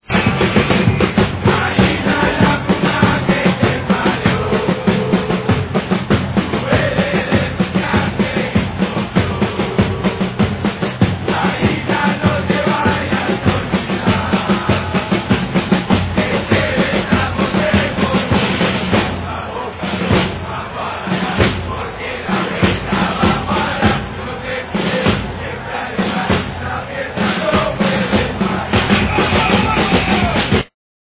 gallinas